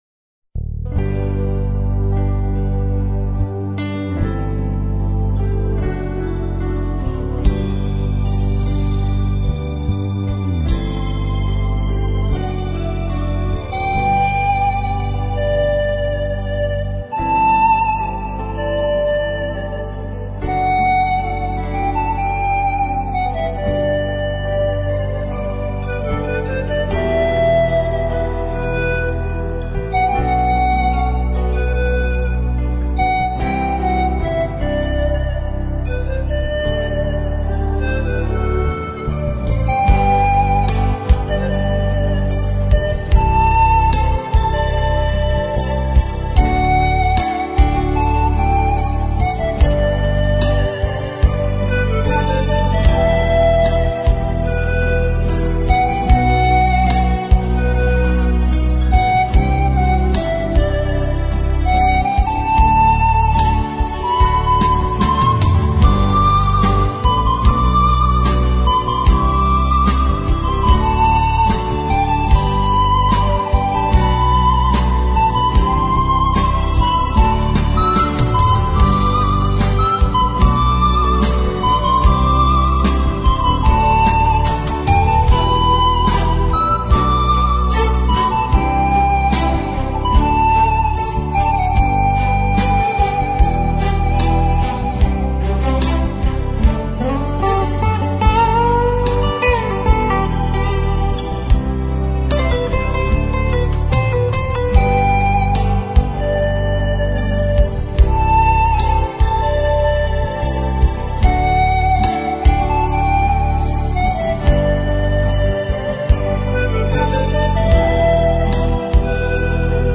Entre-Instrumentais